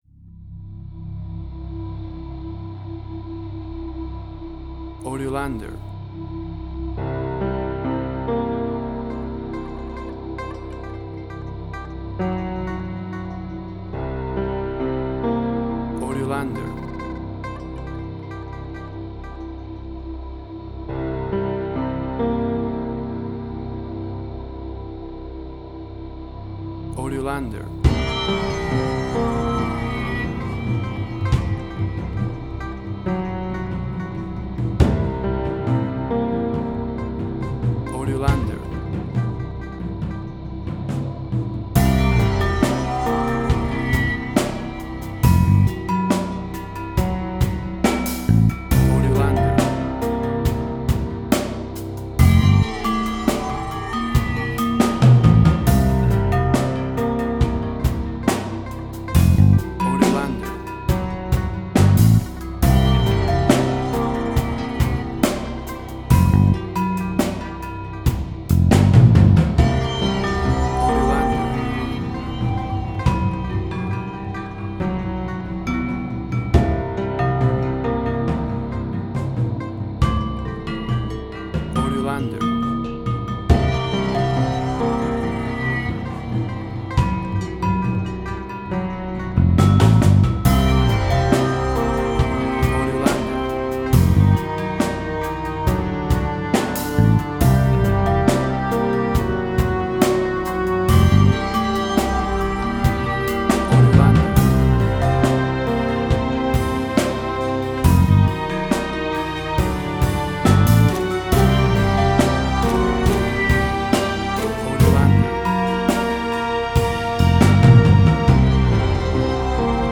Suspense, Drama, Quirky, Emotional.
Tempo (BPM): 69